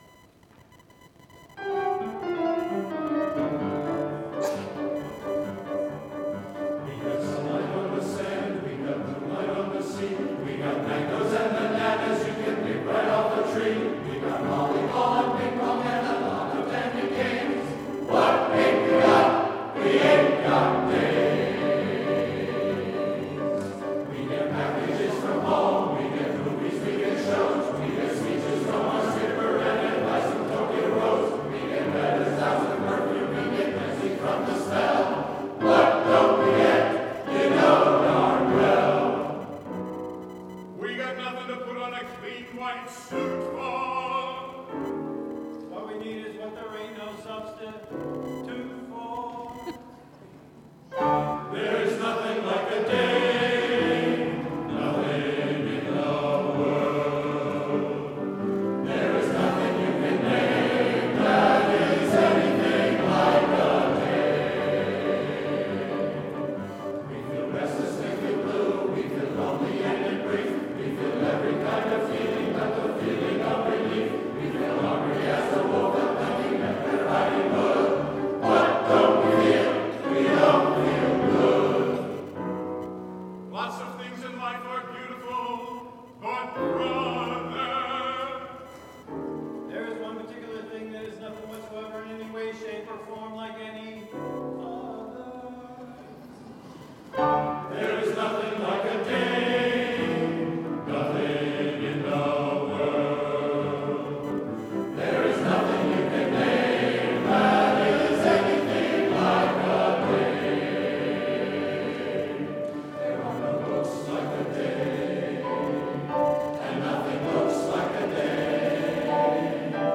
August 25, 2024 - Summer Harmony Concert
Summer Harmony Chorus, 2024